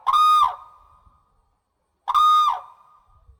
Black-necked Crane
Guard Call | A sharp, single call expressing alarm.
Black-necked-Crane-Alarm.mp3